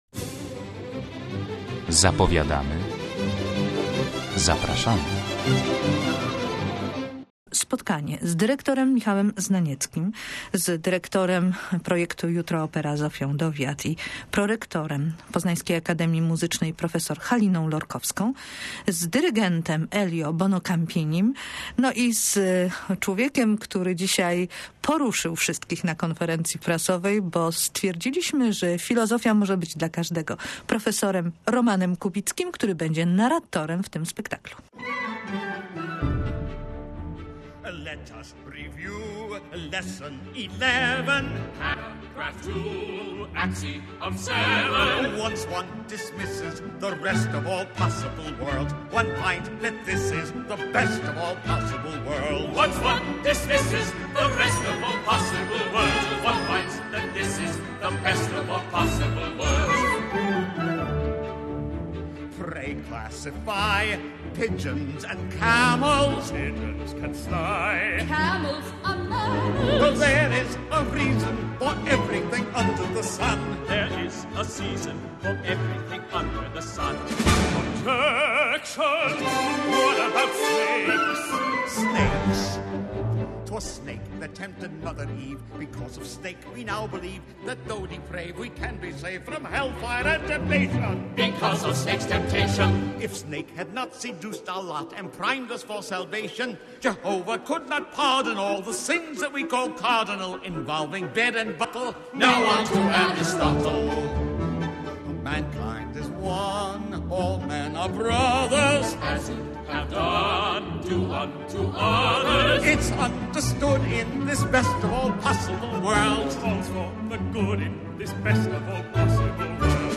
Przed premierą „Kandyda” Leonarda Bernsteina odbyła się w Teatrze Wielkim konferencja prasowa.